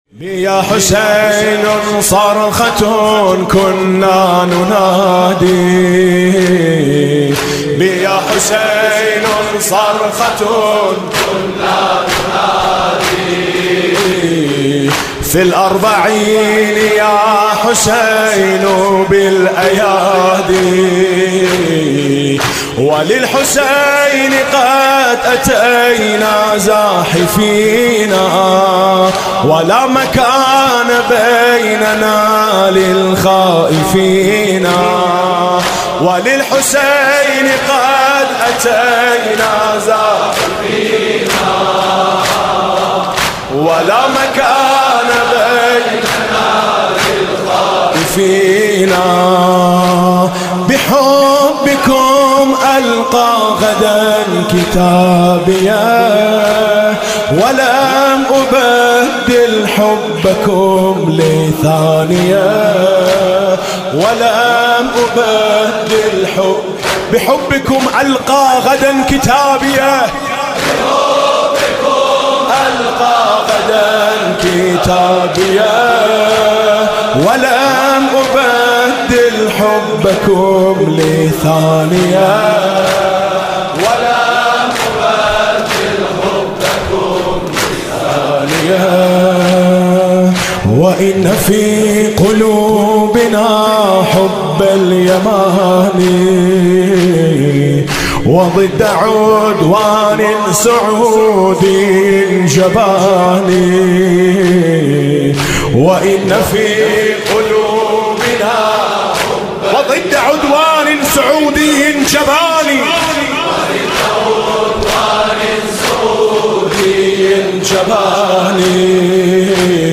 مداحی های کامل میثم مطیعی؛ دهه اول محرم 94